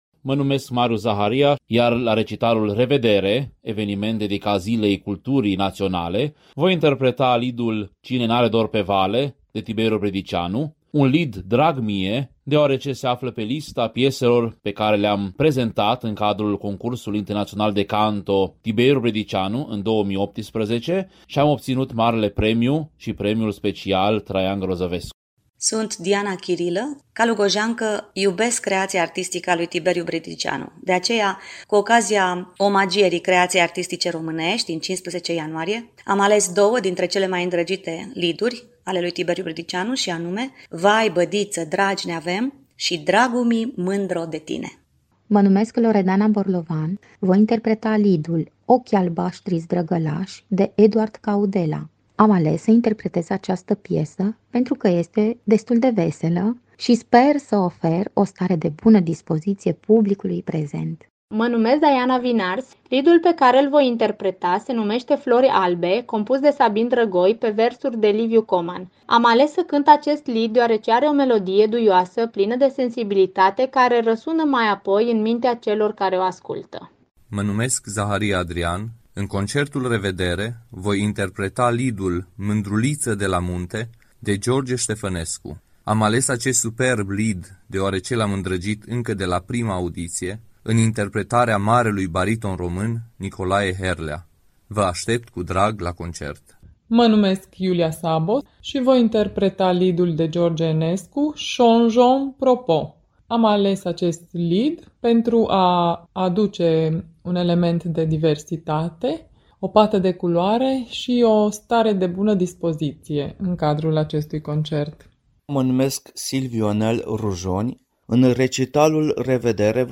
Declarații, în exclusivitate pentru Radio Timișoara, din partea artiștilor lirici ai Corului Operei Naționale Române din Timișoara, protagoniștii Recitalului de lieduri Revedere”, organizat de ONRT: